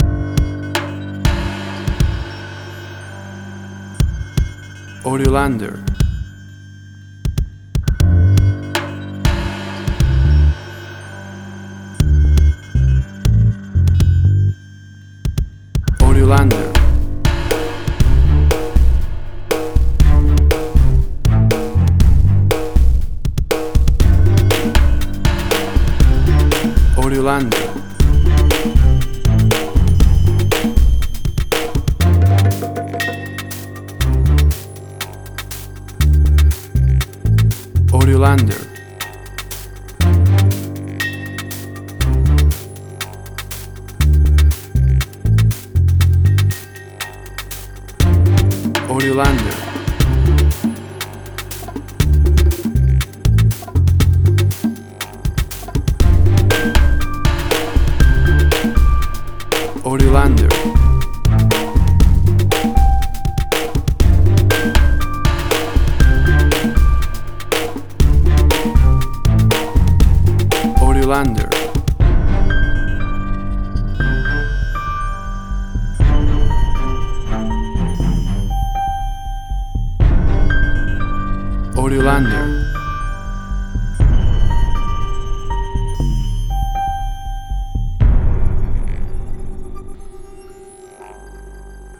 Suspense, Drama, Quirky, Emotional.
Tempo (BPM): 121